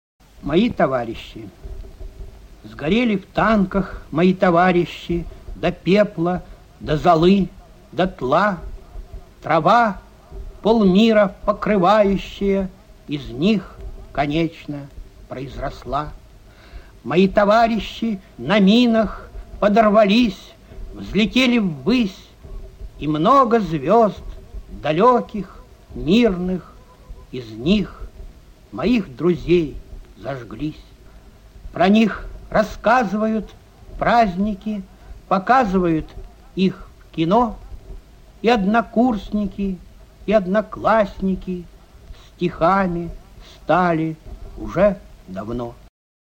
1. «Борис Слуцкий – Мои товарищи (читает автор)» /